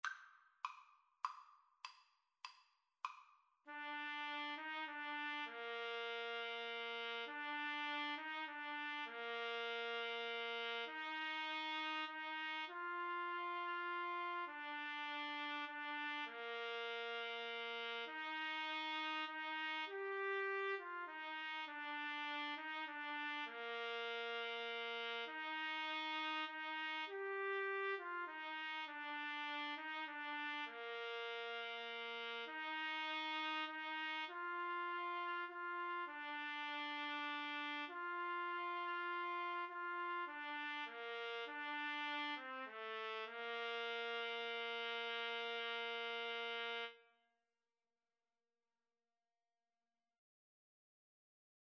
6/8 (View more 6/8 Music)